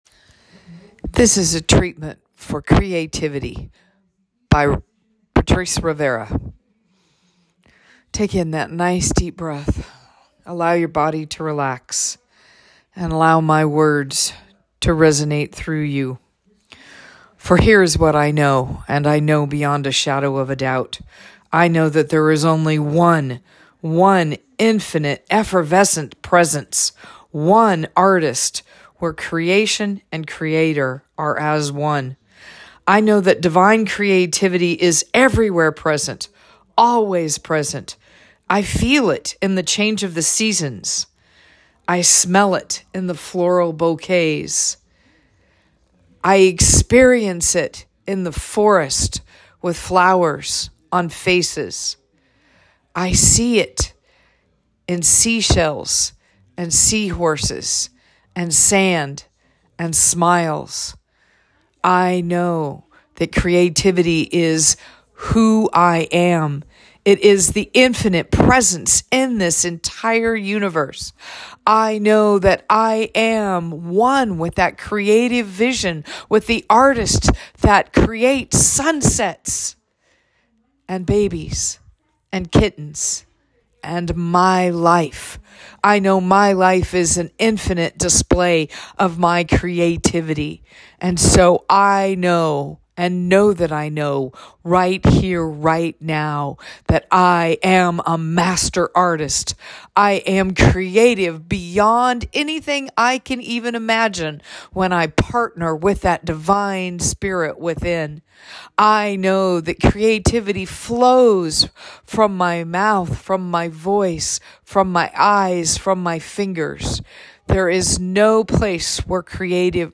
Recorded Prayers